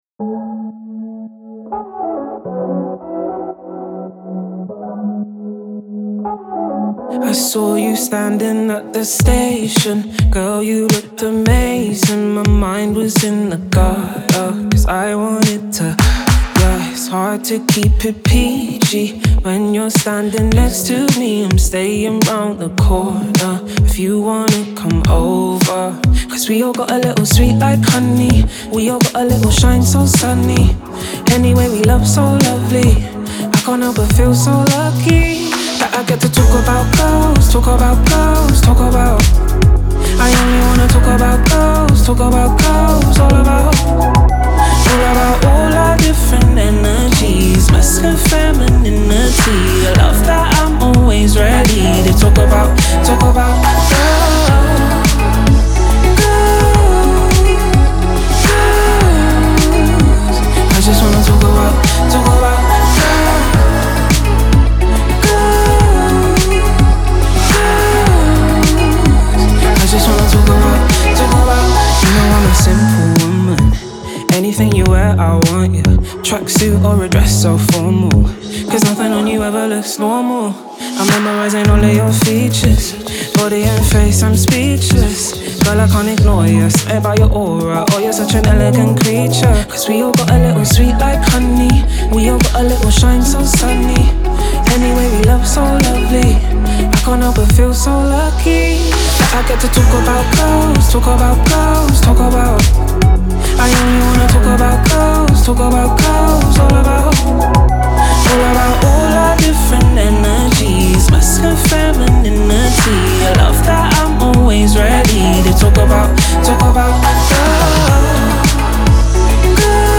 Британская поп сенсация.